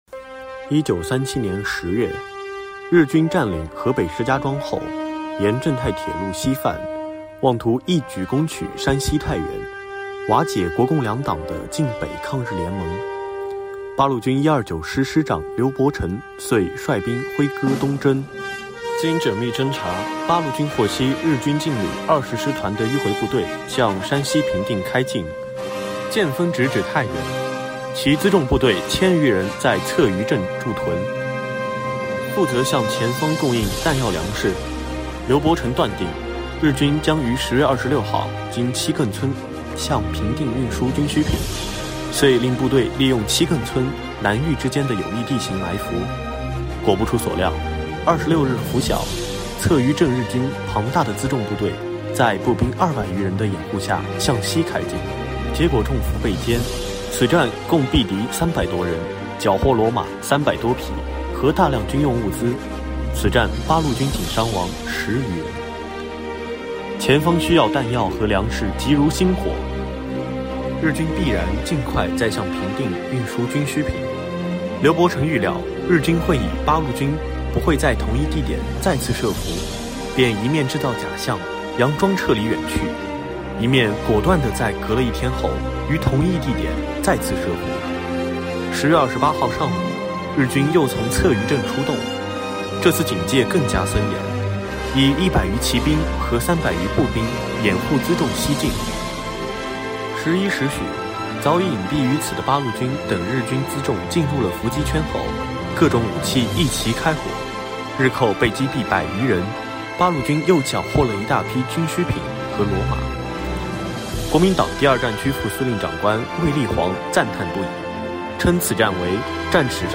讲党史故事